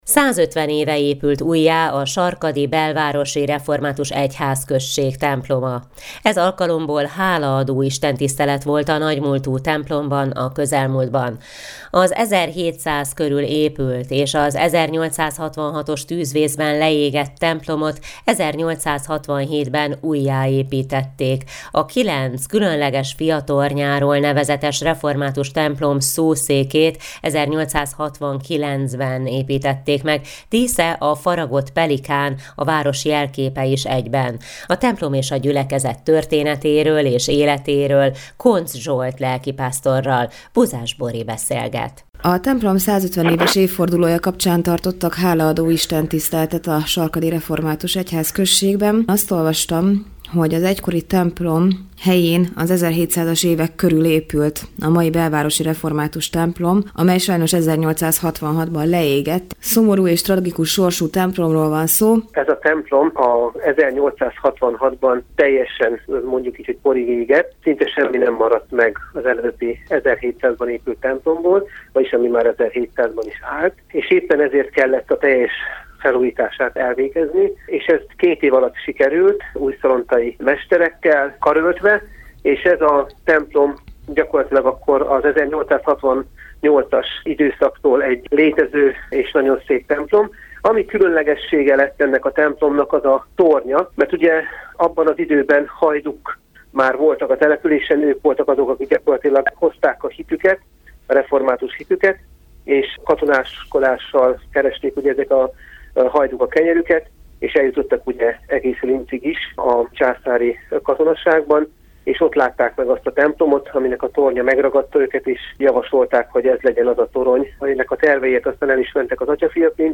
Hálaadó Istentisztelet a Sarkad-Belvárosi Református Templom 150 éves újjáépítése alkalmából - hanganyaggal